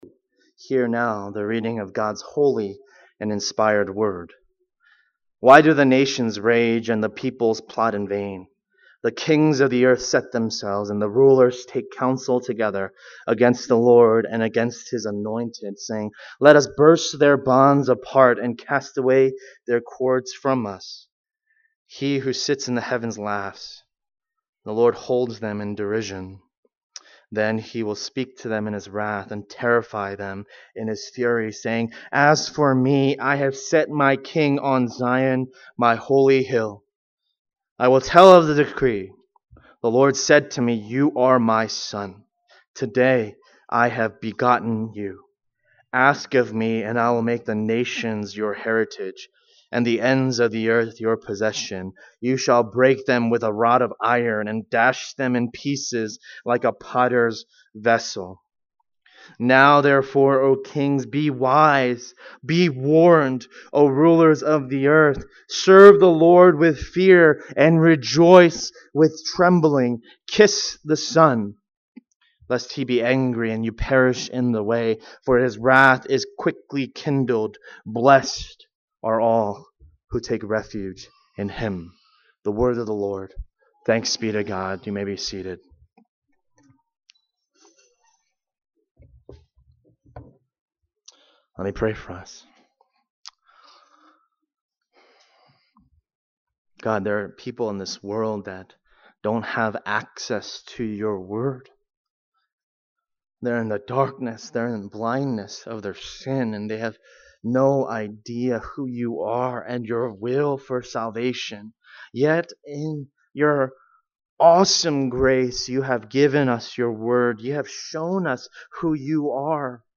The Songbook of God's People Passage: Psalm 2:1-12 Service Type: Sunday Afternoon « Introduction